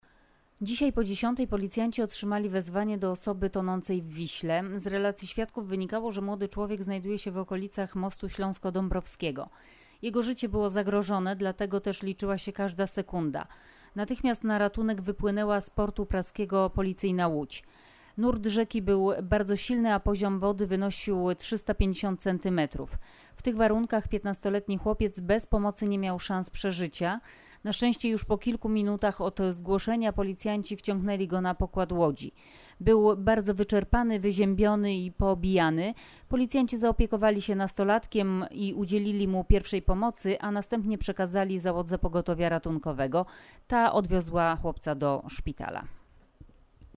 Nagranie audio wypowiedż